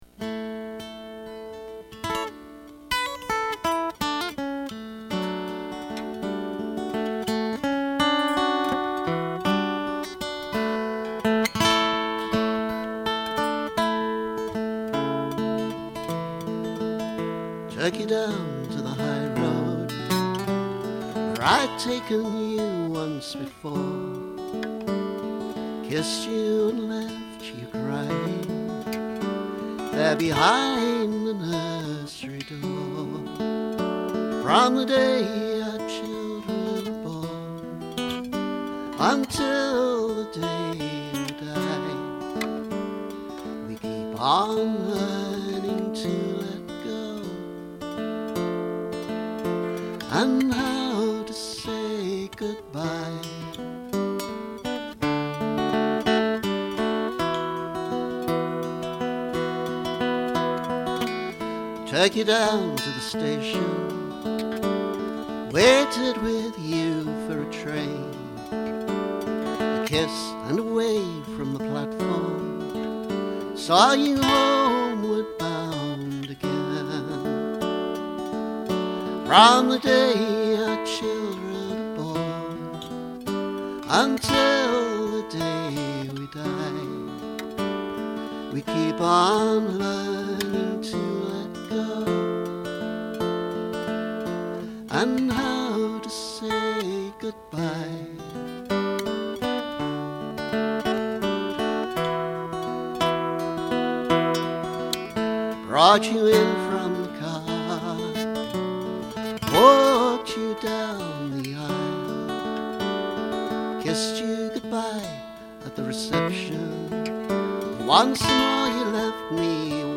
How to say goodbye (live version)